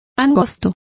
Also find out how angostas is pronounced correctly.